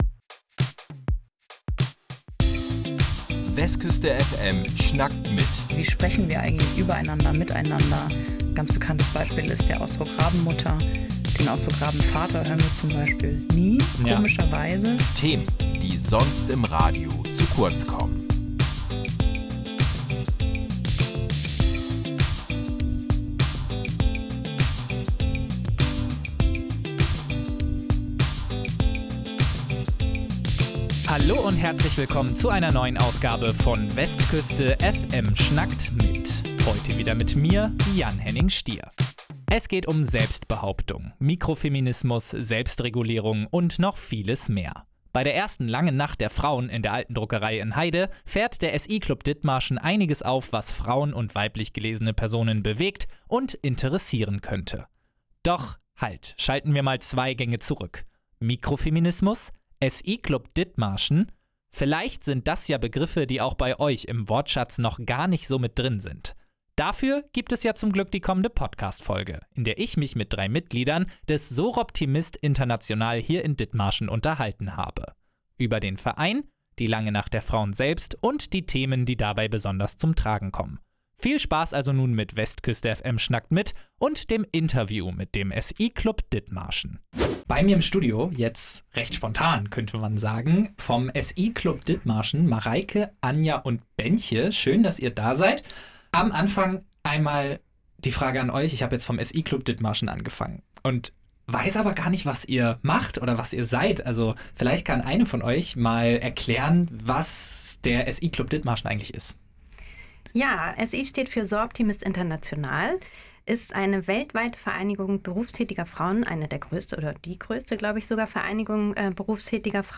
Wir haben die Veranstalterinnen vom SI Club bei uns im Interview zu der Veranstaltung ausgefragt.